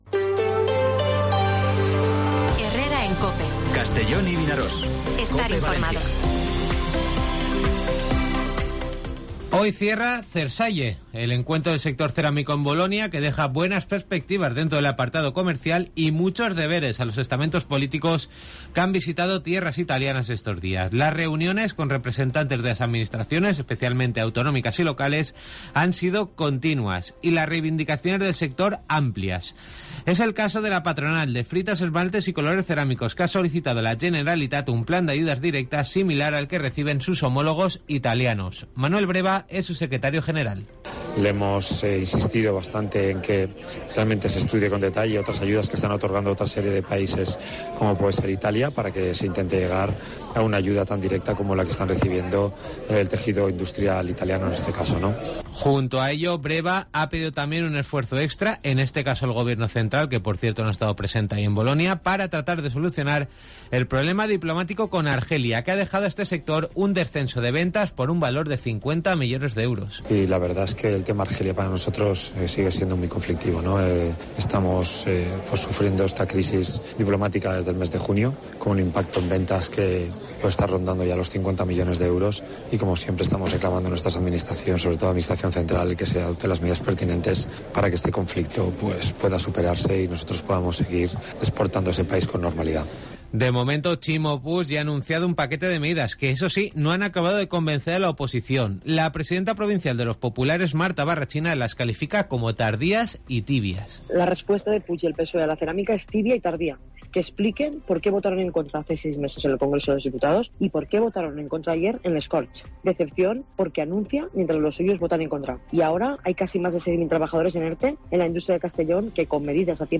Informativo Mediodía COPE en Castellón (30/09/2022)